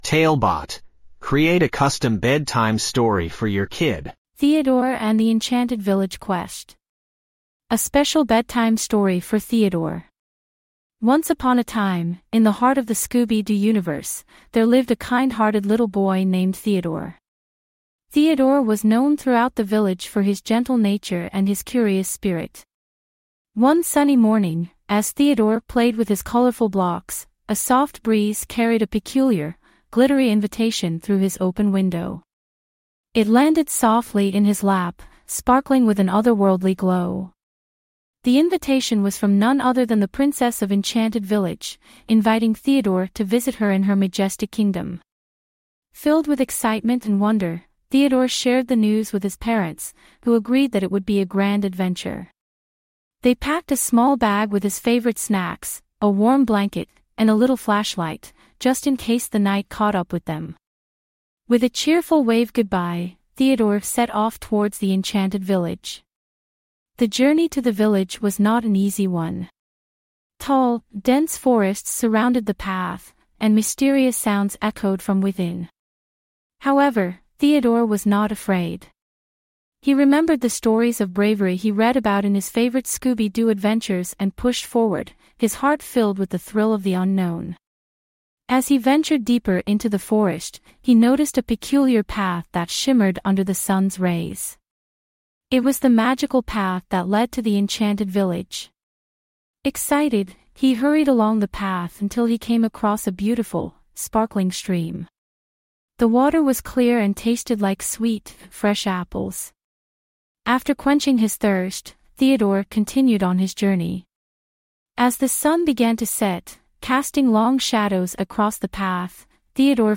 5 minute bedtime stories.
Write some basic info about the story, and get it written and narrated in under 5 minutes!